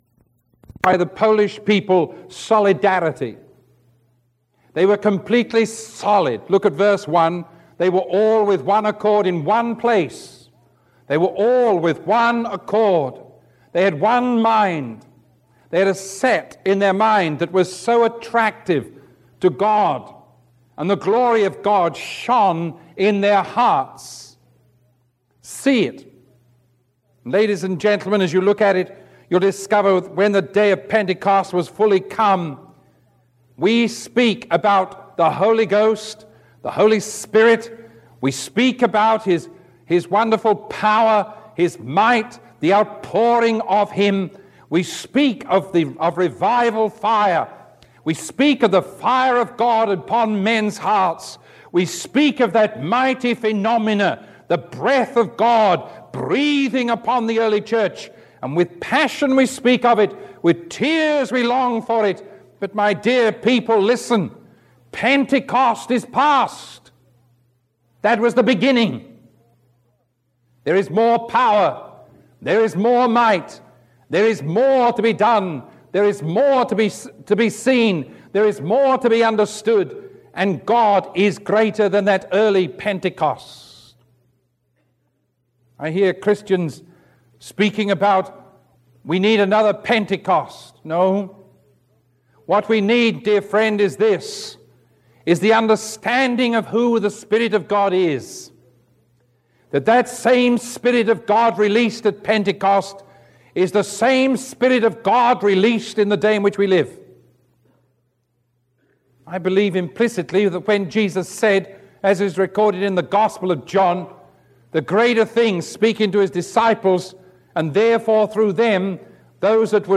Sermon 0961B recorded on December 30